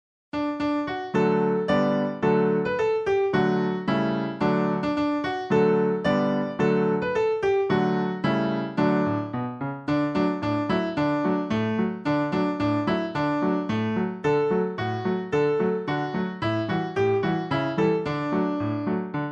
Traditional French Song Lyrics and Sound Clip